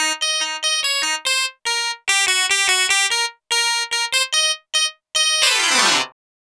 Twisting 2Nite 4 Clav-D.wav